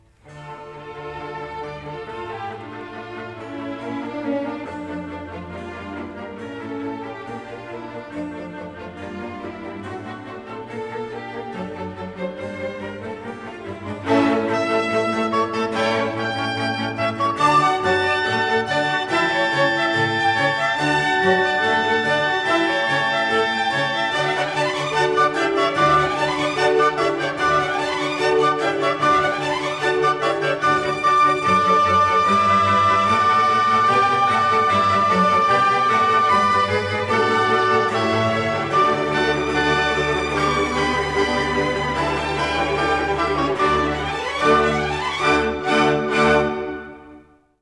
This is the Allegro in a performance by the